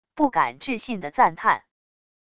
不敢置信的赞叹wav音频